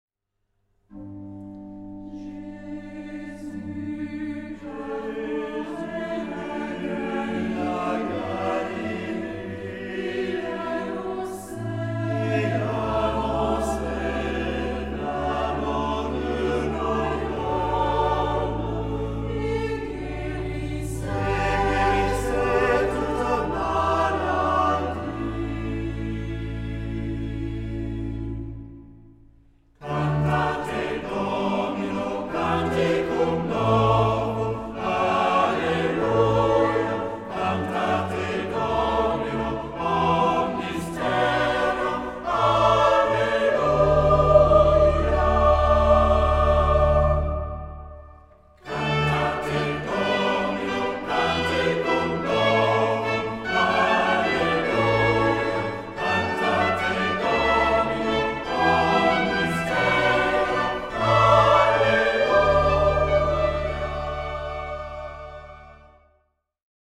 Genre-Style-Form: Sacred ; troparium ; Psalm
Mood of the piece: collected
Type of Choir: SATB  (4 mixed voices )
Instruments: Organ (1) ; Melody instrument (1)
Tonality: D major